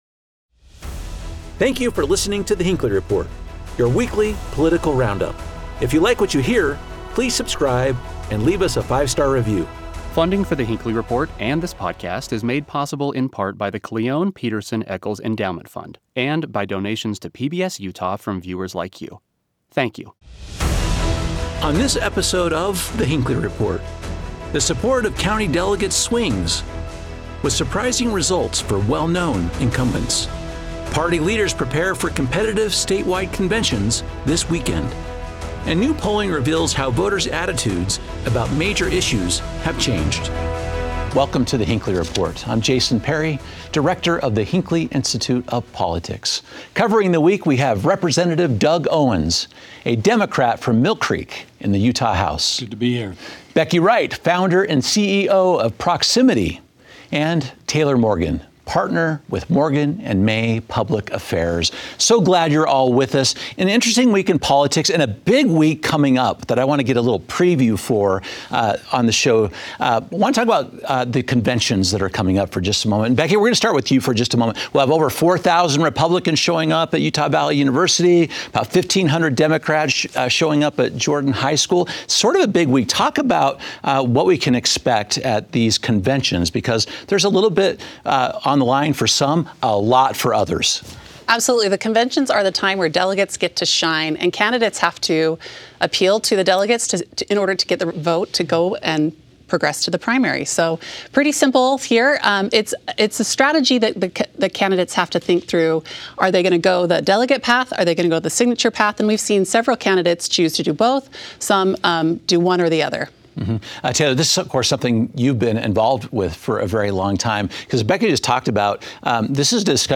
Our expert panel discusses some of the top contests and examines what dynamics will be at play.